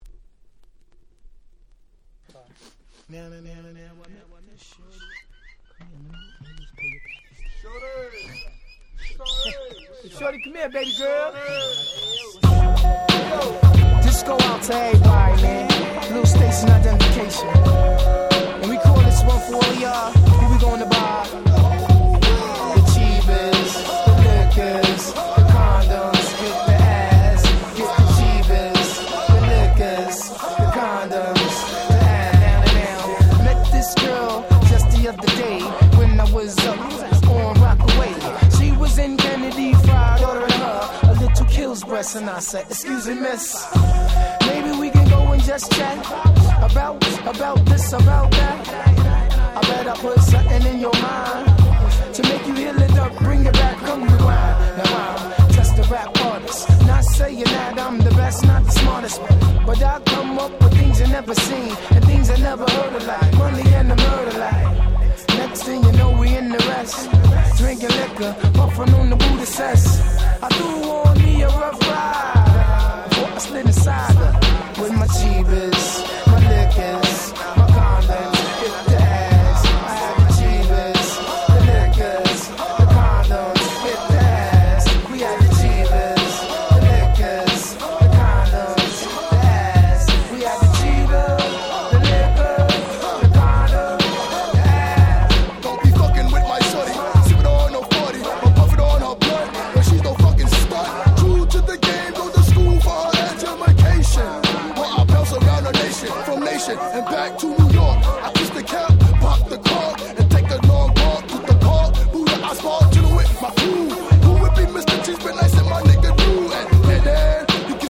96' Nice Hip Hop !!
イナタいBeat90's臭プンプンです！！
「これぞ90's Hip Hop !!」な1枚です。
Boom Bap